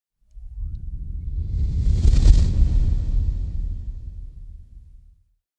Звуки кометы
Кинематографический звук кометы пролетающей вблизи